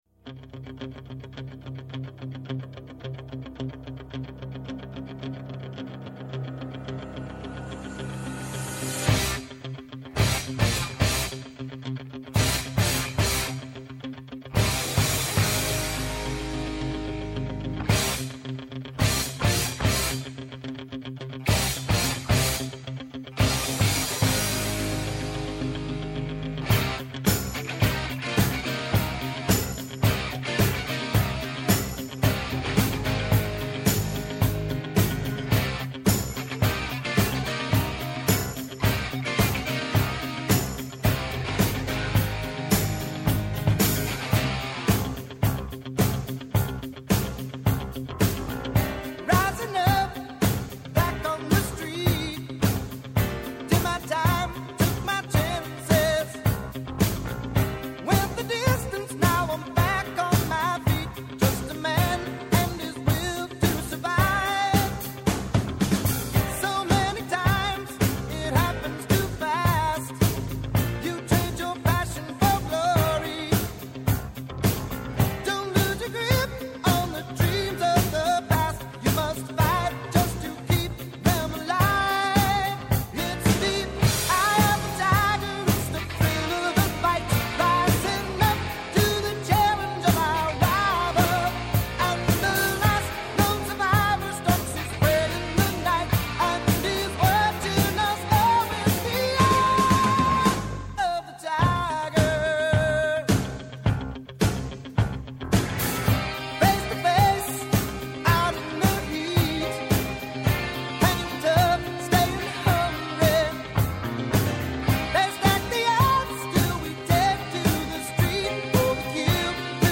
Σήμερα η εκπομπή είναι αφιερωμένη στα παιδιά με καλεσμένους που μιλούν για την σημασία της ενασχόλησής τους με τον αθλητισμό και την αξία της αλληλεγγύης.
ΠΡΩΤΟ ΚΑΙ ΣΤΑ ΣΠΟΡ, η κοινωνική διάσταση του αθλητισμού, από τις συχνότητες του Πρώτου Προγράμματος της Ελληνικής Ραδιοφωνίας κάθε Σάββατο 13:00-14:00 το μεσημέρι από το Πρώτο Πρόγραμμα.